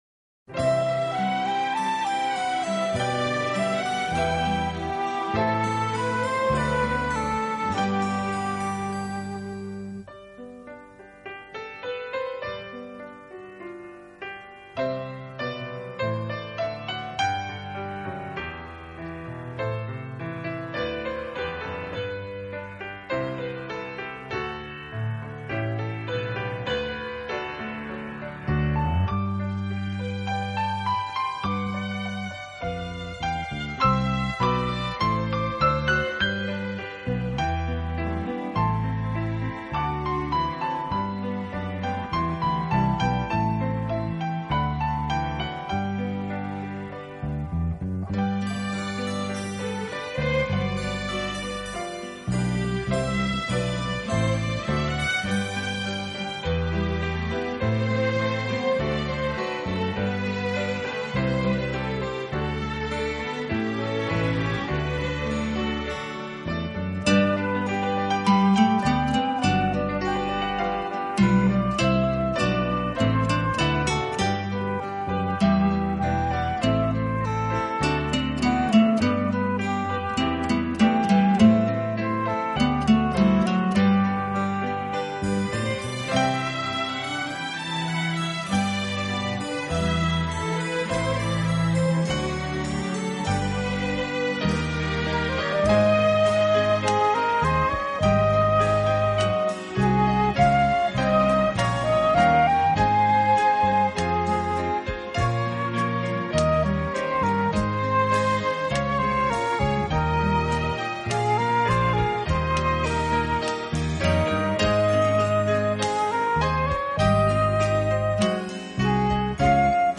Genre......: Instrumental
经典歌曲等，经不同乐器演奏，谱写出一个个浪漫的诗